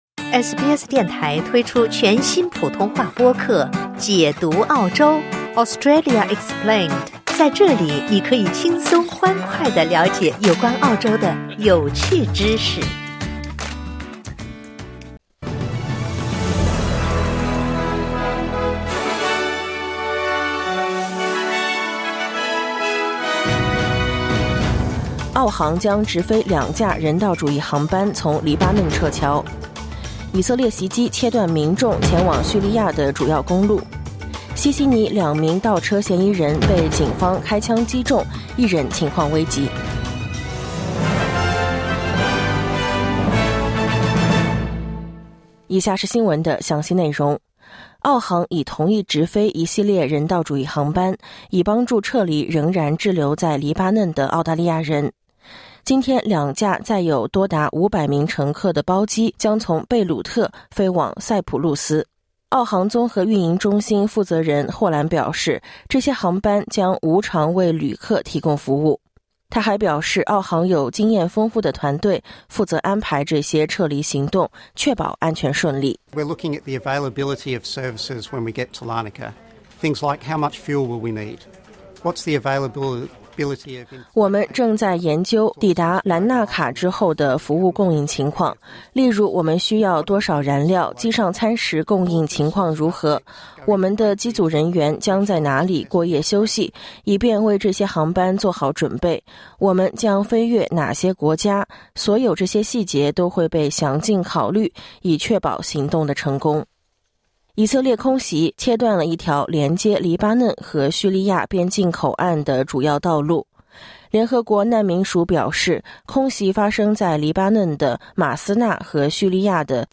SBS早新闻（2024年10月5日）
SBS Mandarin morning news Source: Getty / Getty Images